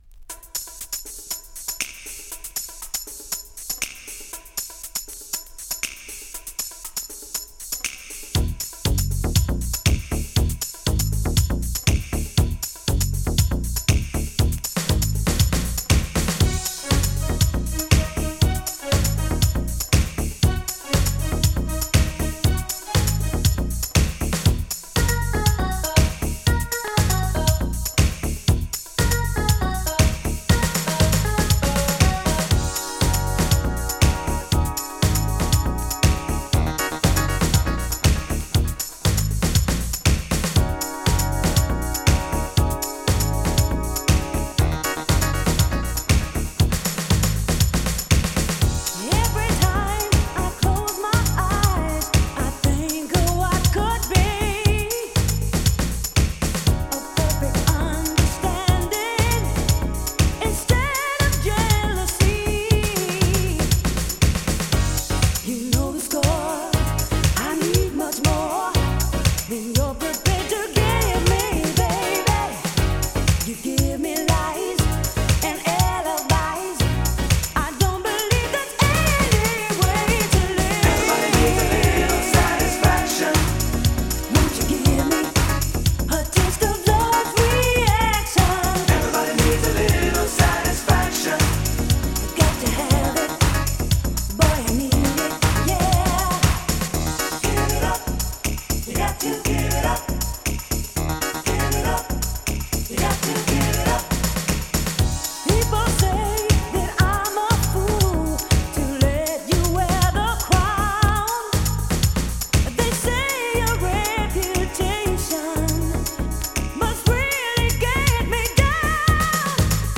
[HOUSE] [UK]
Early house from the UK!
UK early house featuring soulful female vocals!